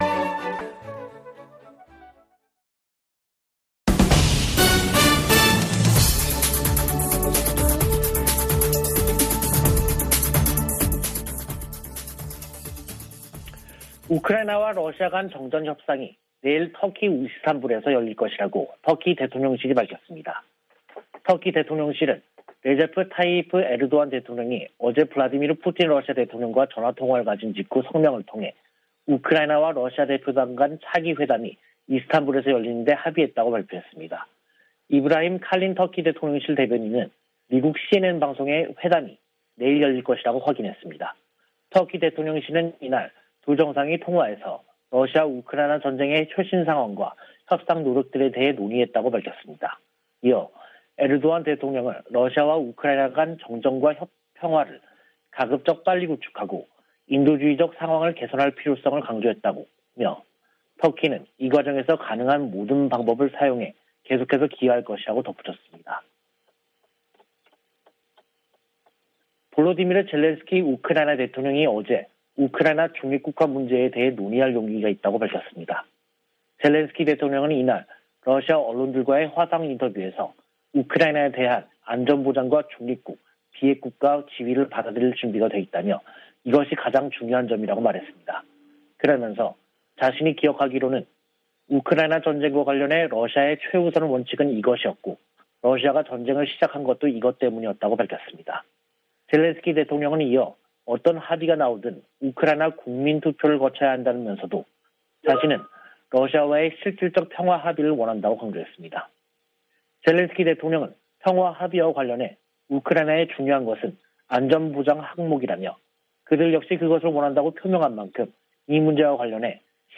VOA 한국어 간판 뉴스 프로그램 '뉴스 투데이', 2022년 3월 28일 2부 방송입니다. 유엔 안보리가 북한 ICBM 발사에 대응한 공개 회의를 개최하고 규탄했으나 언론 성명 채택조차 무산됐습니다. 미 국무부는 중국과 러시아가 북한의 추가 도발 자제를 위해 강력한 메시지를 보내야 한다고 강조했습니다. 최근 ICBM 발사는 북한이 지난 몇 달간 보여준 도발 유형의 일부이며 추가 발사에 나설 것이라고 백악관 국가안보좌관이 전망했습니다.